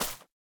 1.21.5 / assets / minecraft / sounds / block / vine / break2.ogg
break2.ogg